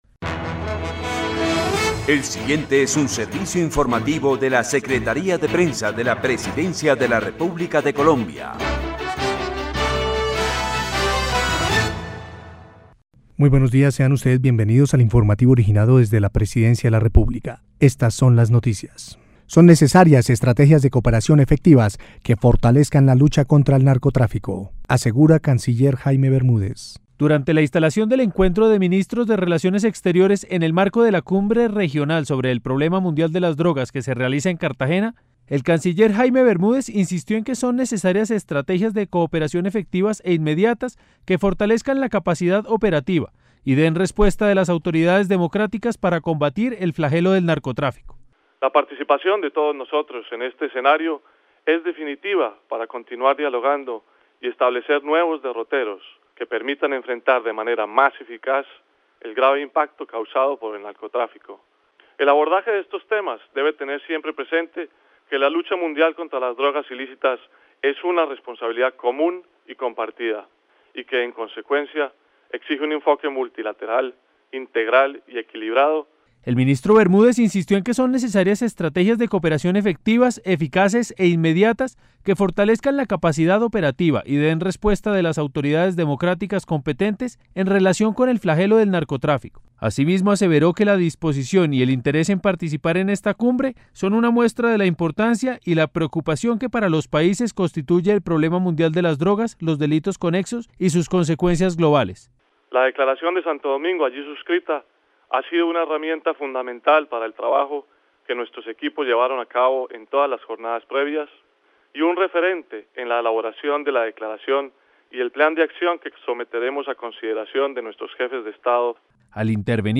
La Secretaría de Prensa de la Presidencia presenta a sus usuarios un nuevo servicio: El Boletín de Noticias, que se emite de lunes a viernes, cada tres horas, por la Radio Nacional de Colombia, en las frecuencias 570 AM y 95.9 FM.